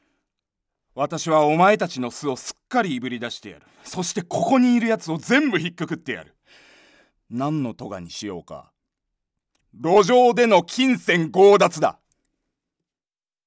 セリフA
ボイスサンプル